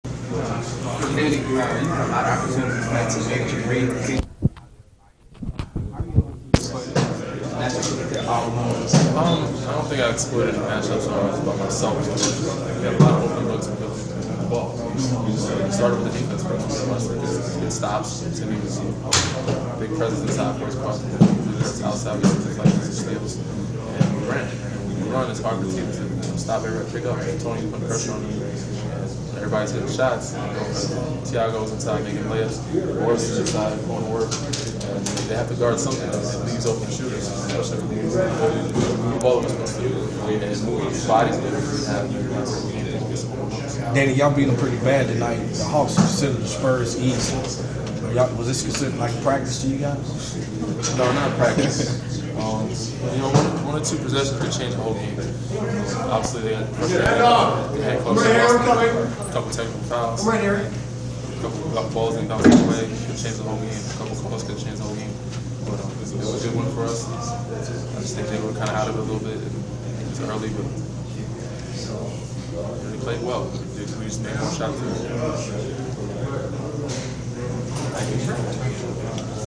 Inside the Inquirer: Postgame presser with San Antonio Spurs’ guard Danny Green (3.22.15)
We attended the postgame presser of San Antonio Spurs’ guard Danny Green following his team’s 114-95 road victory over the Atlanta Hawks on Mar. 22.
San Antonio Spurs’ Danny Green postgame interview vs. Atlanta Hawks 3/22/15